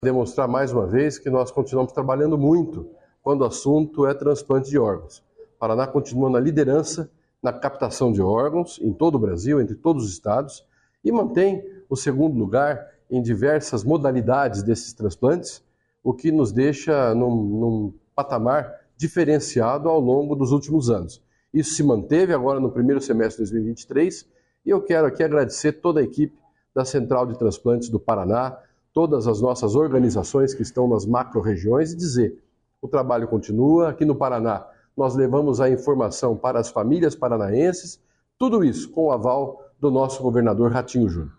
Sonora do secretário da Saúde, Beto Preto, sobre os resultados do Paraná no ranking nacional de doação de órgãos